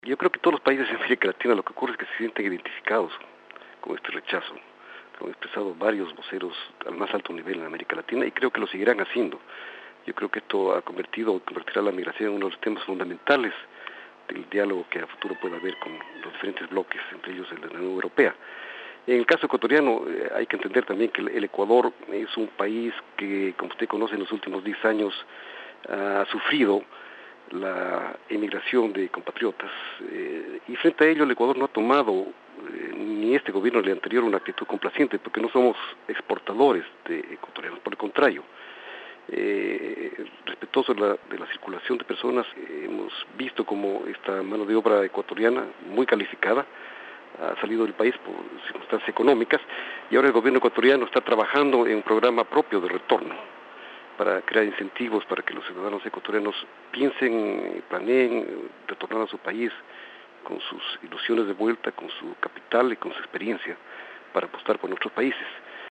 Jaime Marchán, embajador de Ecuador ante Suiza, en entrevista con swissinfo.